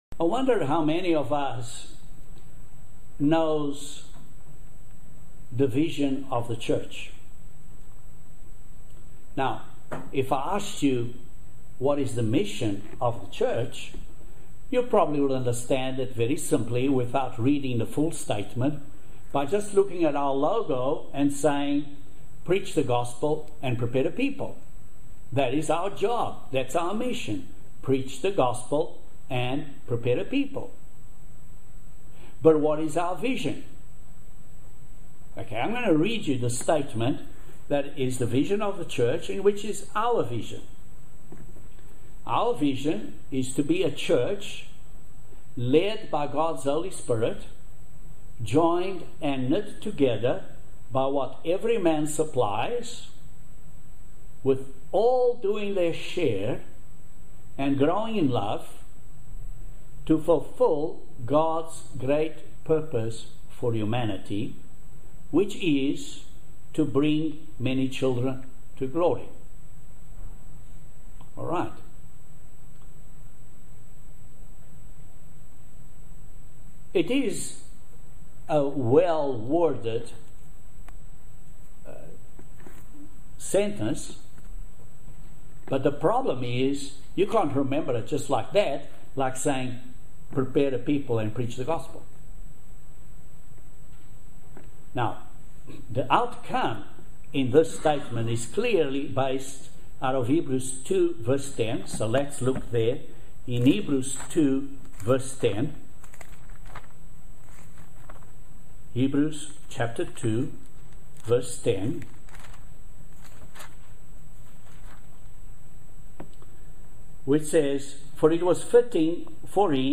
Join us for this excellent video sermon on the truth in love.